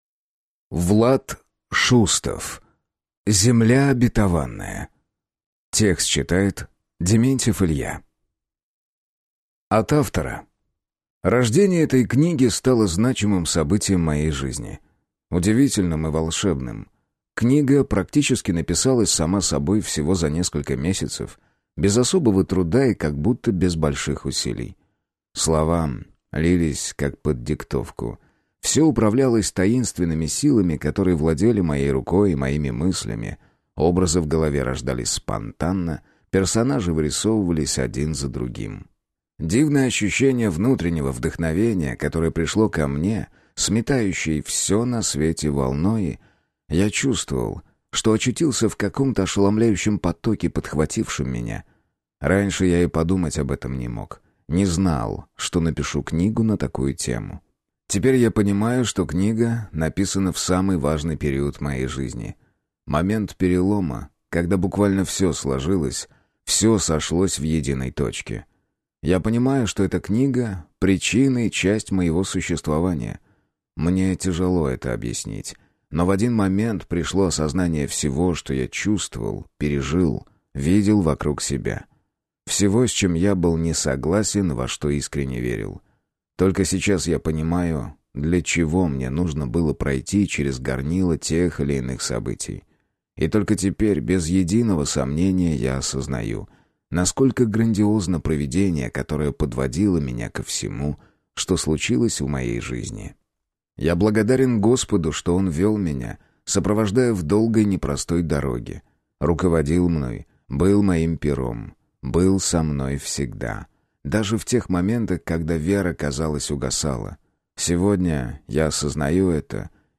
Аудиокнига Земля обетованная | Библиотека аудиокниг